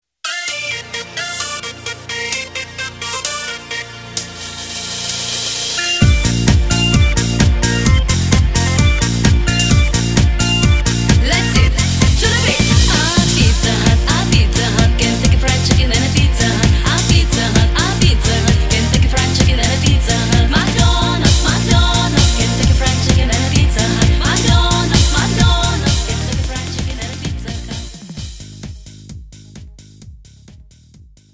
Backing track files: 2000s (3150)
Buy With Backing Vocals.
Buy Without Backing Vocals